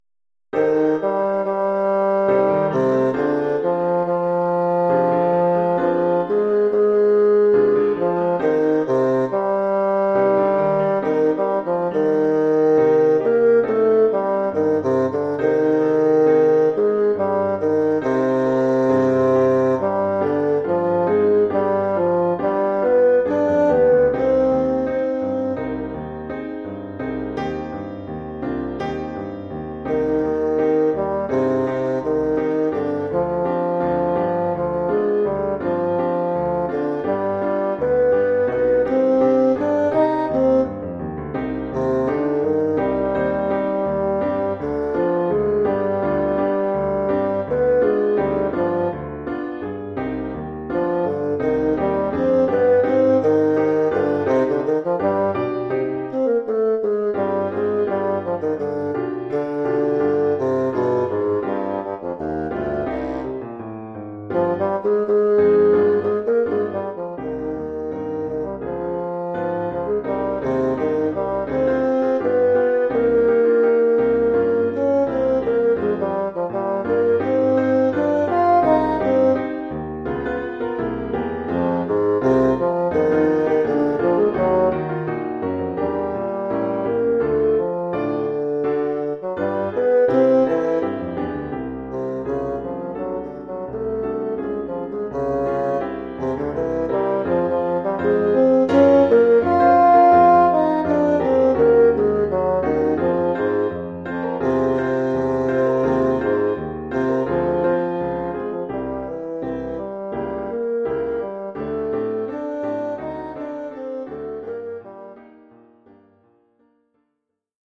1 titre, basson et piano : conducteur et partie de basson
Oeuvre pour basson et piano.